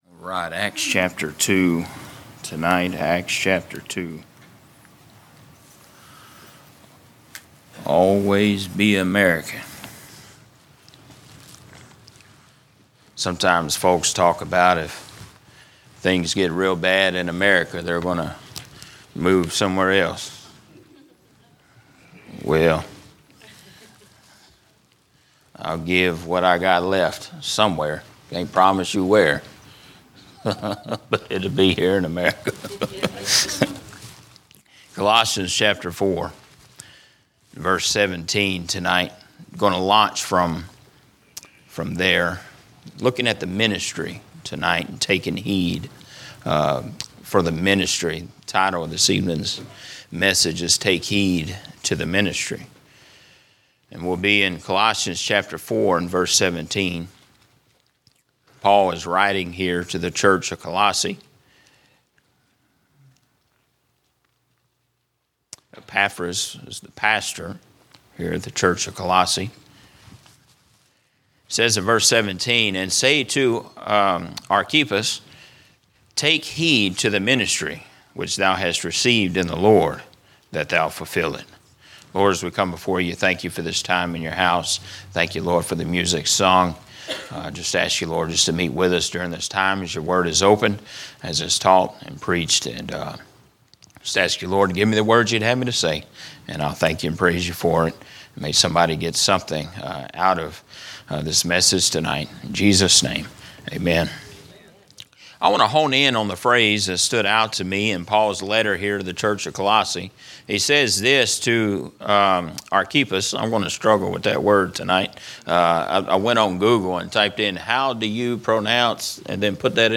" General Preaching " Sermons not part of a specific series